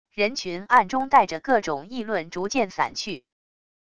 人群暗中带着各种议论逐渐散去wav下载